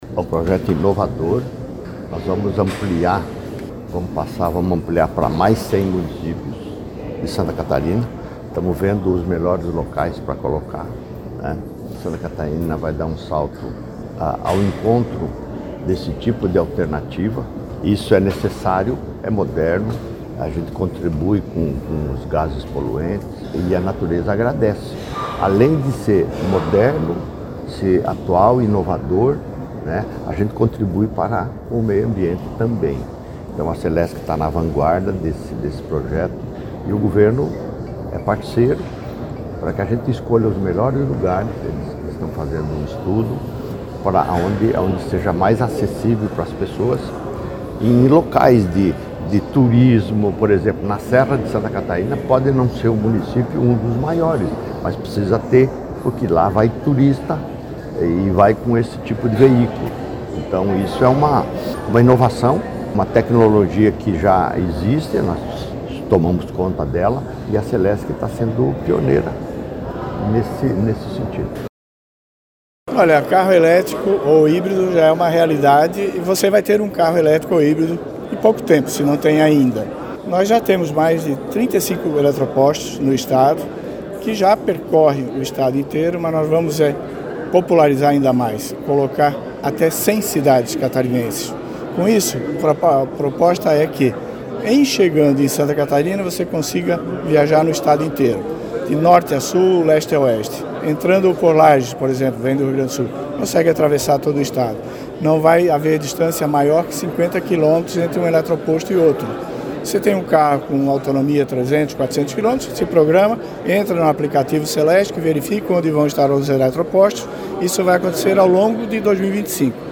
O governador Jorginho Mello destacou que essa nova fase do Corredor Elétrico Catarinense é reflexo de um esforço Santa Catarina vai dar um salto neste tipo de alternativa:
SECOM-Sonoras-Expansao-do-Corredor-Eletrico-Catarinense-1.mp3